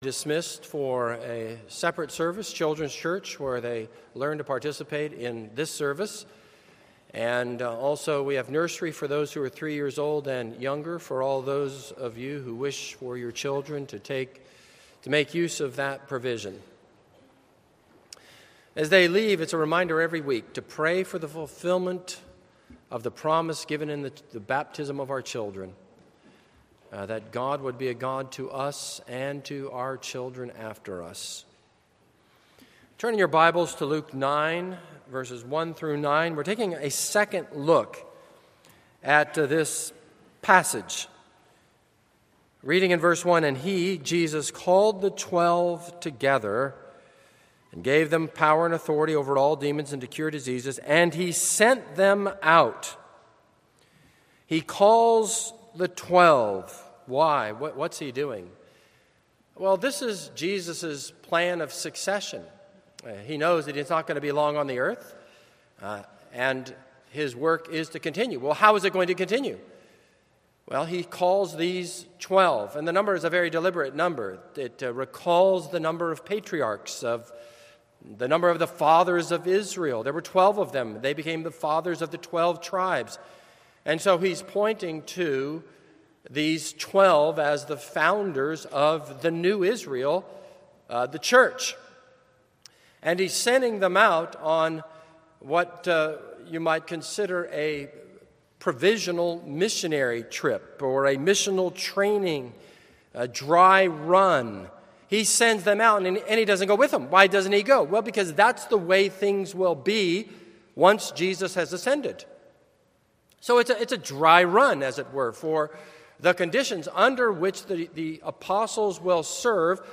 This is a sermon on Luke 9:1-9.